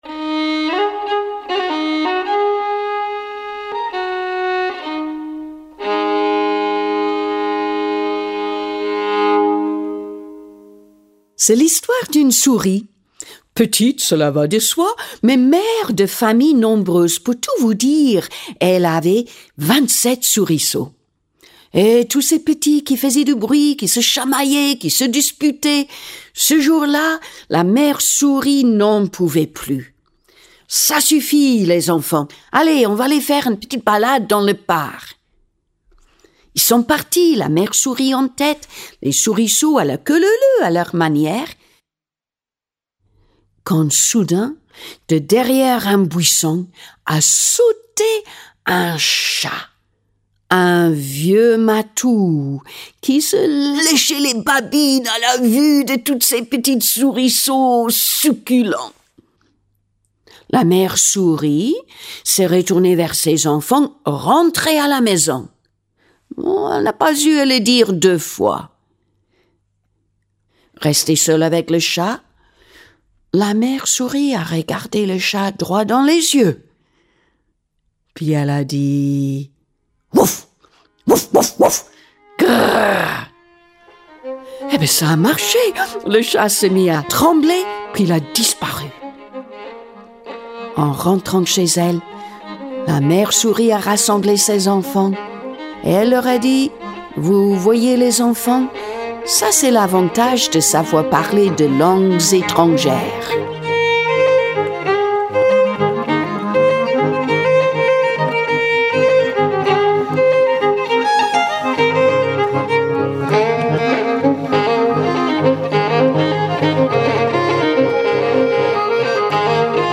c’est un accent délicieux et la générosité des paysages qui habillent ses histoires. Des contes en forme d’invitation au voyage en Écosse, où, en plus des châteaux hantés, des êtres fantastiques, s’ajoute naturellement, l’histoire du benêt local, Daft Donald : celui à qui il manque des clous dans la tête.
Contes d’auteurs